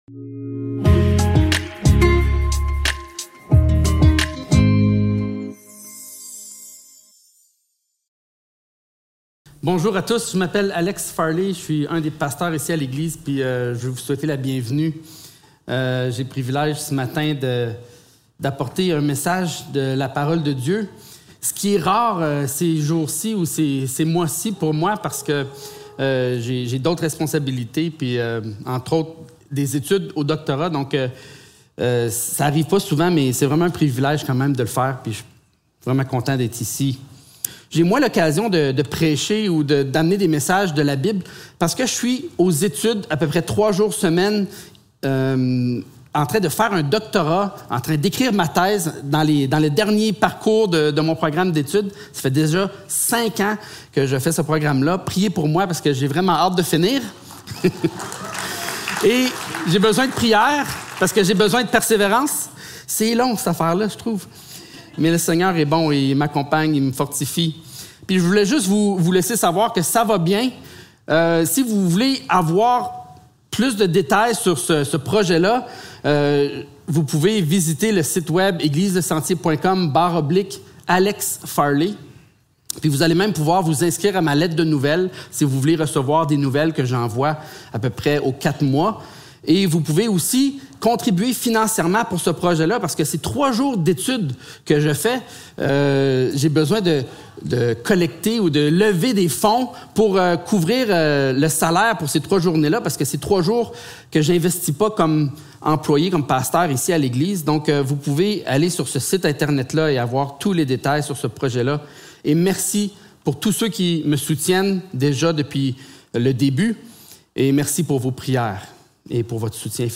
Célébration dimanche matin